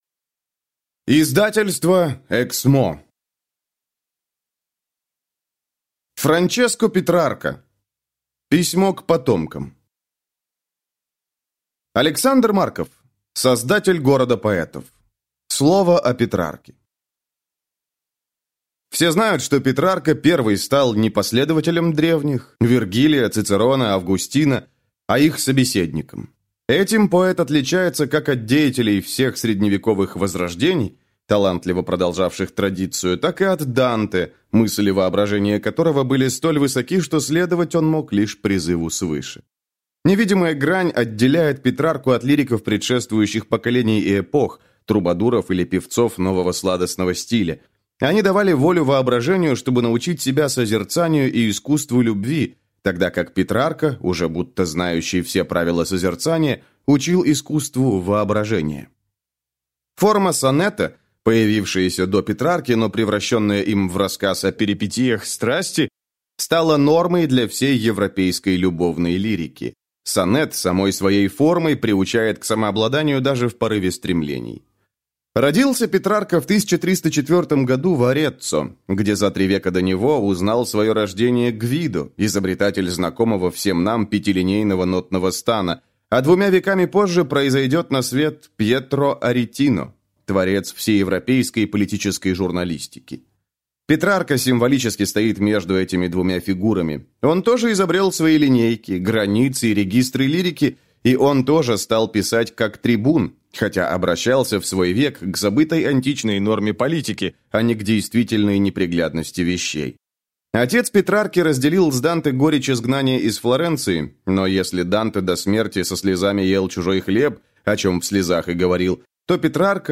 Аудиокнига Письмо к потомкам | Библиотека аудиокниг